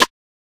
SizzleTM88Rimshot.wav